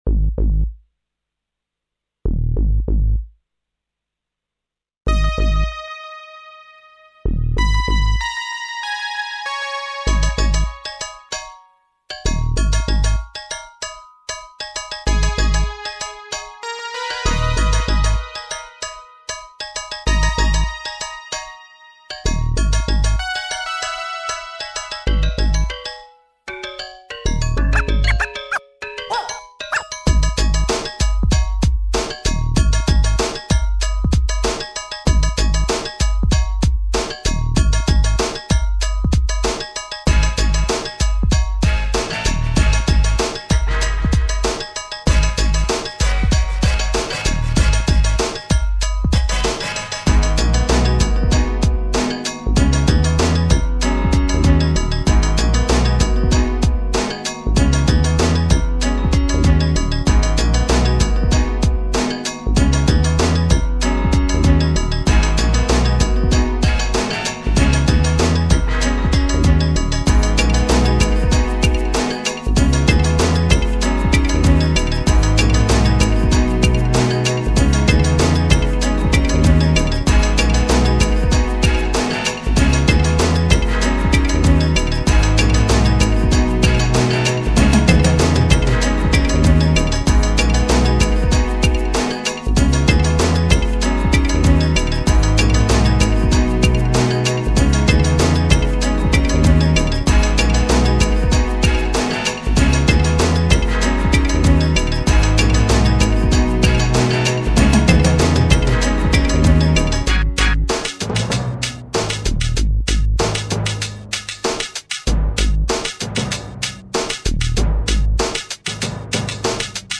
3,95 MB 2003 Pop / Hip Hop